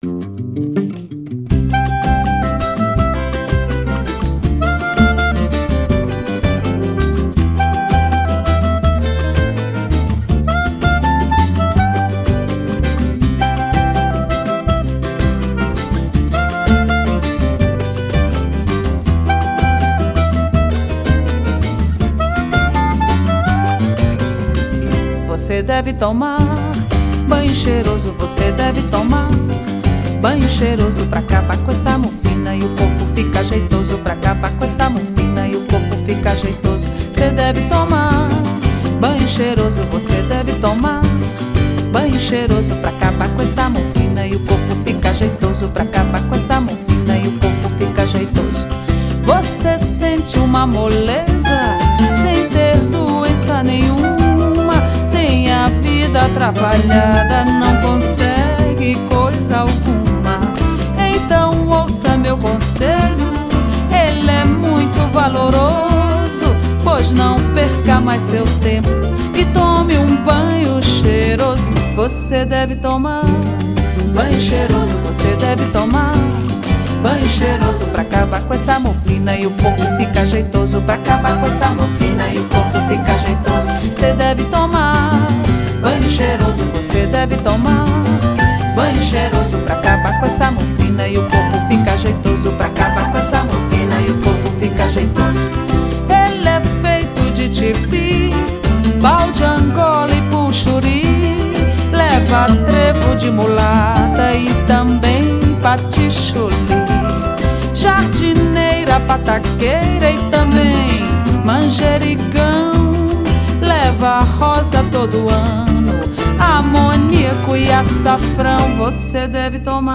Brazilian singer